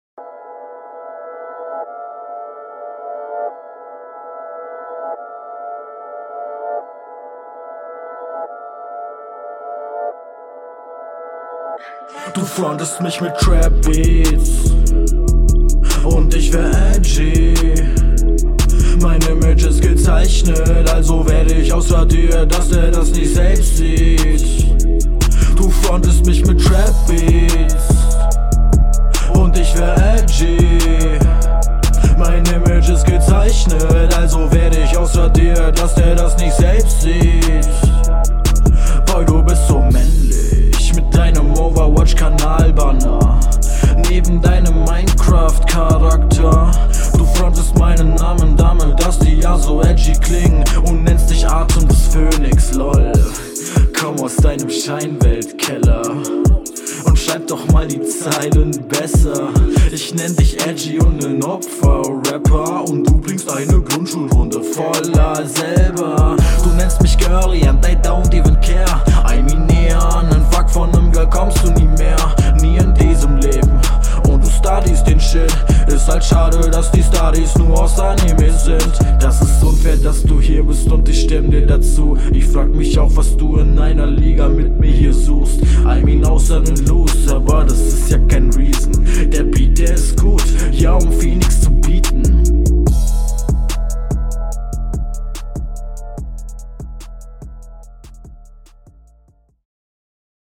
Adlips stabil.
Stimmung bockt, Betonung auch cool.
Flow: viel Straighter und schneller, geht mehr nach vorne, (außer die Hook am anfang die …
Is halt wieder absolut stabil und hat coole Betonungen, …